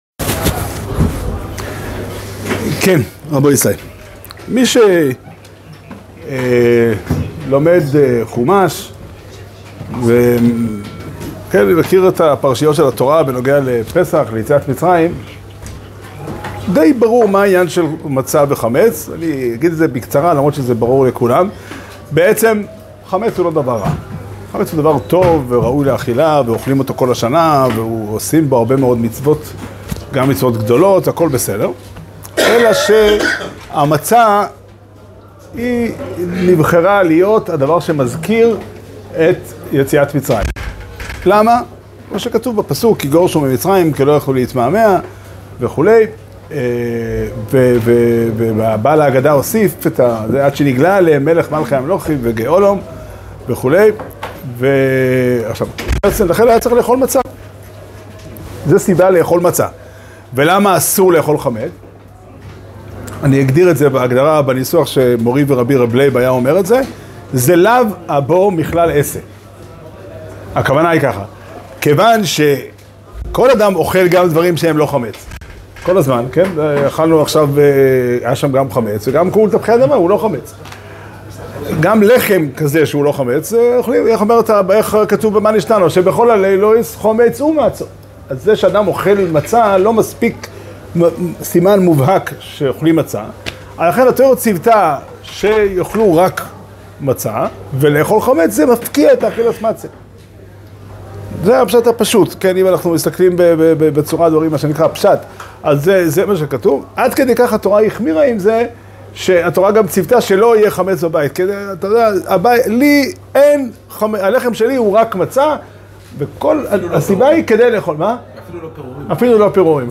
שיעור שנמסר בבית המדרש פתחי עולם בתאריך ג' ניסן תשפ"ד